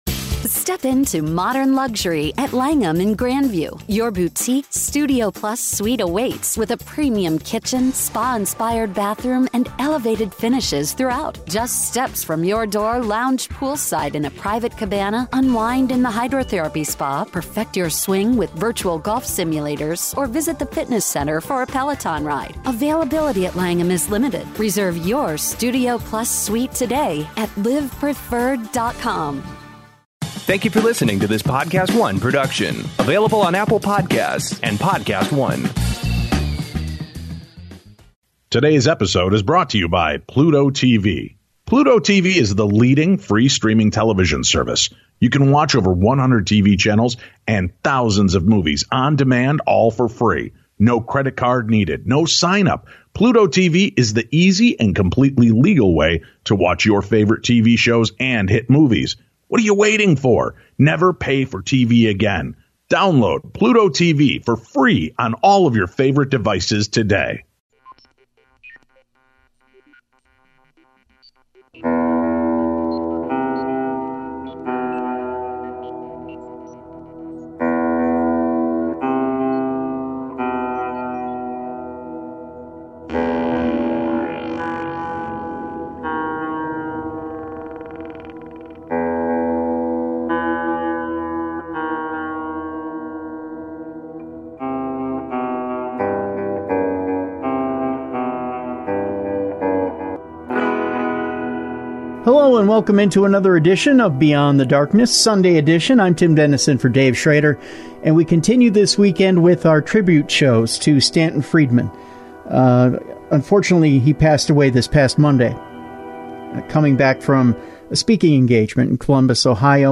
In a weekend of memorial shows dedicated to the life of Ufologist Stanton T. Friedman, BEYOND the DARKNESS offers up an encore presentation of an interview...